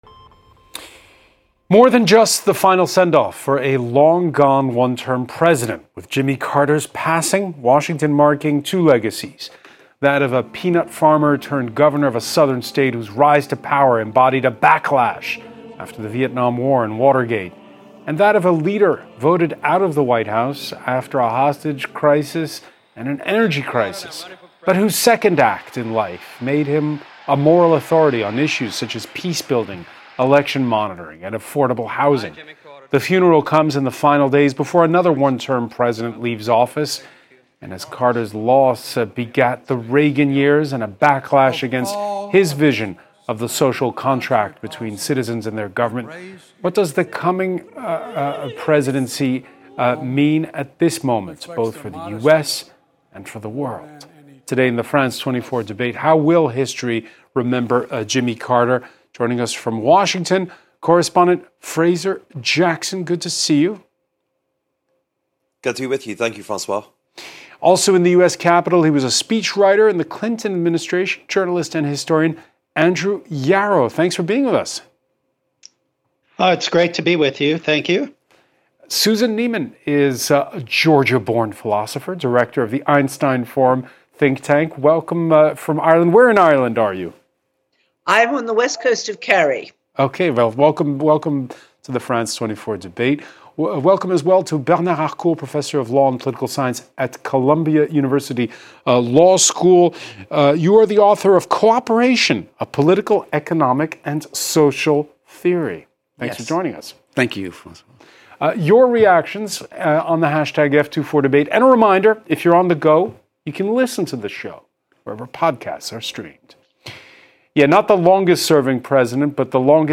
A live debate on the topic of the day, with four guests.